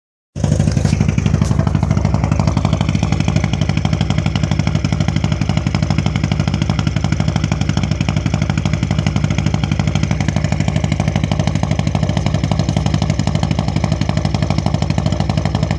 Hier noch ein kleiner Sound-Genuß:
Motor Geräusch Klang Mitsubishi Lancer